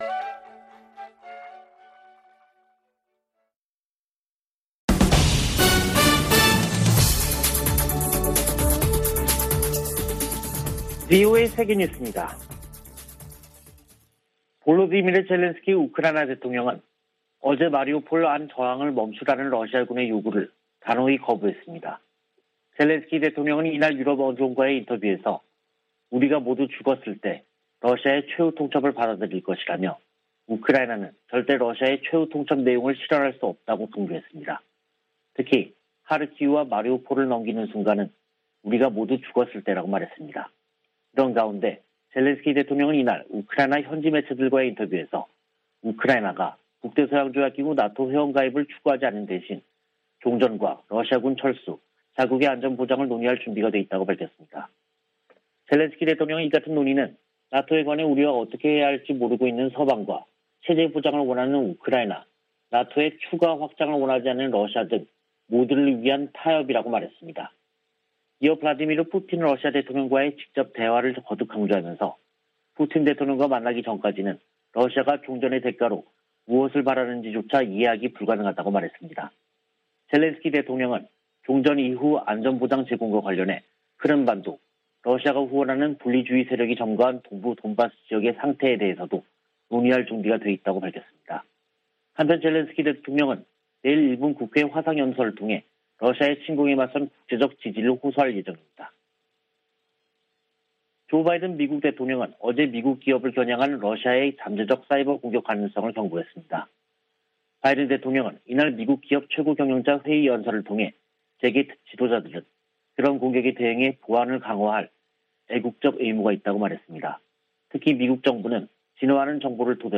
VOA 한국어 간판 뉴스 프로그램 '뉴스 투데이', 2022년 3월 22일 3부 방송입니다. 북한이 연일 미한 연합훈련을 비난하는데 대해, 이는 동맹의 준비태세를 보장하는 주요 방법이라고 미 국방부가 밝혔습니다. 윤석열 한국 대통령 당선인이 북한의 최근 서해상 방사포 발사를 9.19 남북군사합의 위반이라고 말한데 대해 한국 국방부가 합의 위반은 아니라고 밝혀 논란을 빚고 있습니다. 미 의회에서 북한의 사이버 위협에 대응하기 위한 입법 움직임이 활발합니다.